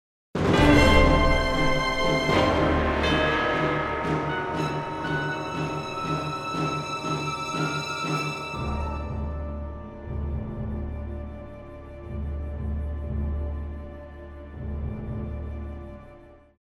Genre: Classical / Halloween
for Solo Viola and Orchestra
Solo Viola and Solo Violin played and recorded by
Virtual Orchestra produced